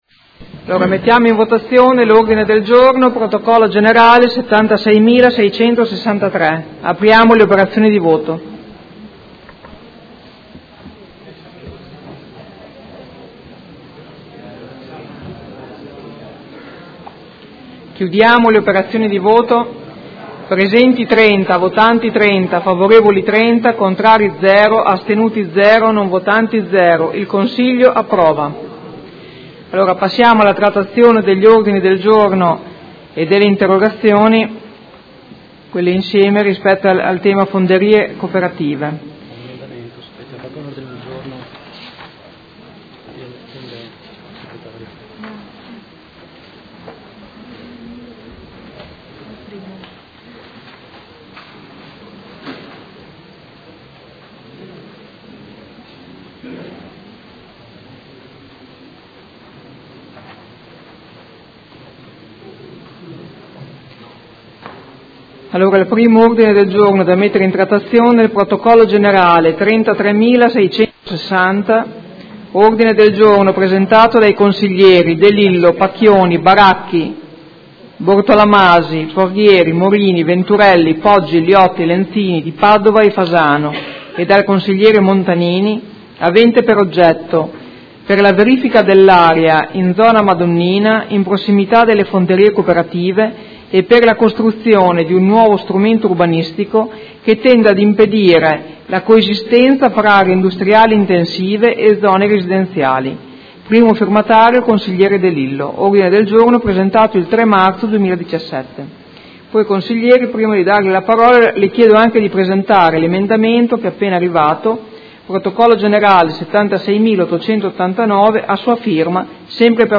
Presidente — Sito Audio Consiglio Comunale
Seduta del 18/05/2017. Mette ai voti Ordine del Giorno firmato dai Consiglieri Carpentieri, Di Padova (P.D.), Scardozzi (M5S), Trande (Art.1- MDP), Campana (Per Me Modena), Montanini (CambiAMOdena) e Santoro (Idea-Popolo e Libertà) avente per oggetto: concerto del gruppo Acciaio Vincente presso il Circolo Terra dei Padri